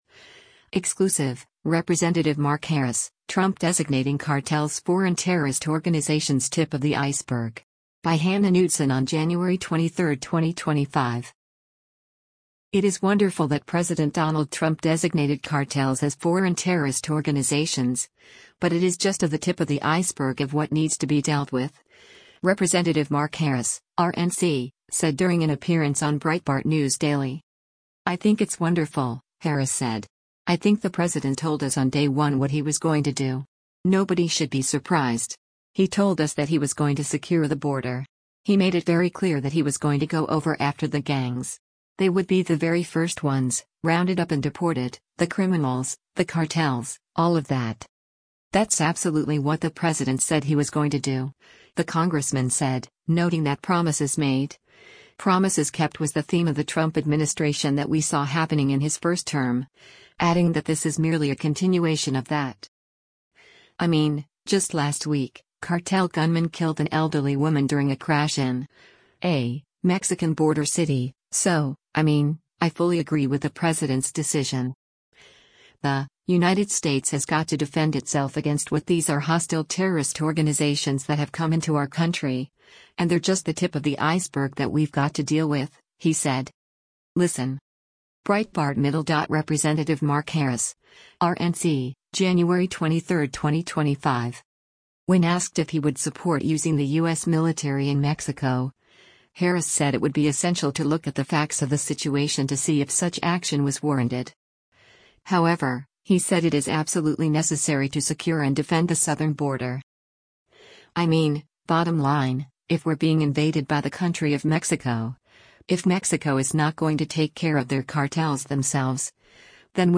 It is “wonderful” that President Donald Trump designated cartels as foreign terrorist organizations, but it is just of the “tip of the iceberg” of what needs to be dealt with, Rep. Mark Harris (R-NC) said during an appearance on Breitbart News Daily.
Breitbart News Daily airs on SiriusXM Patriot 125 from 6:00 a.m. to 9:00 a.m. Eastern.